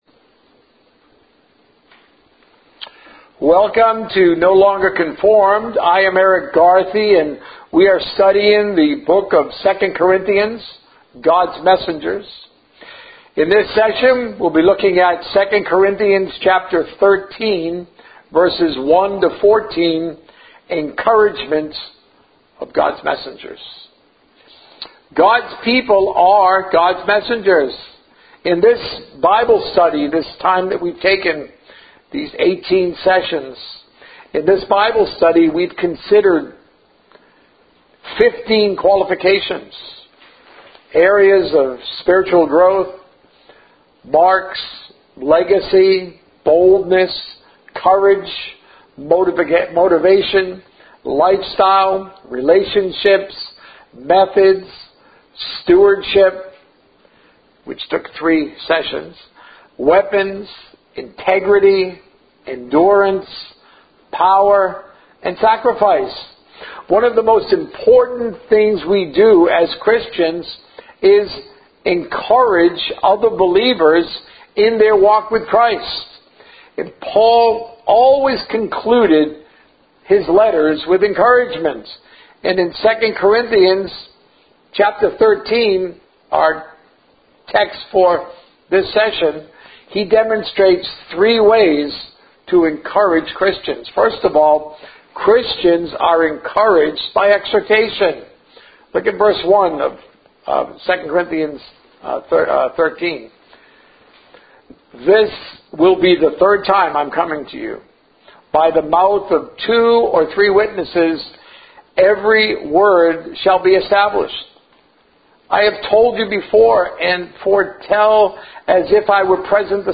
A message from the series "Trusting God."